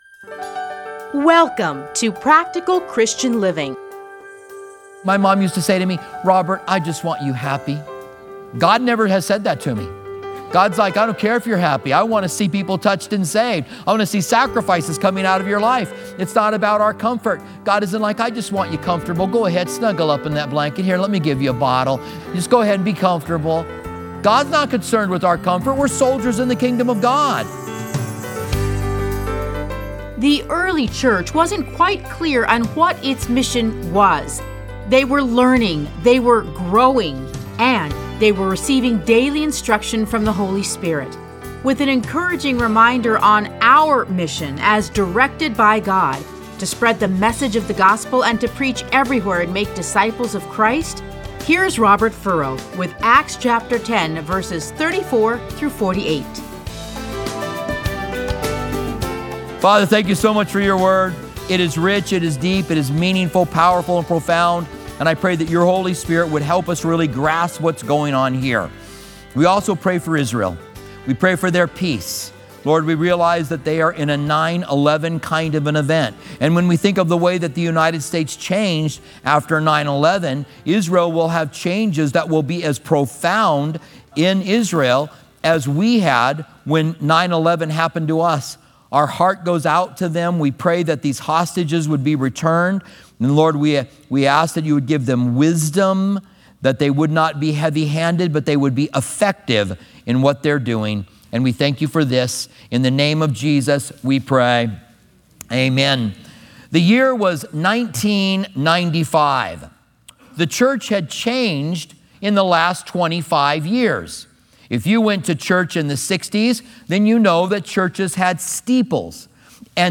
Listen to a teaching from Acts 10:34-48.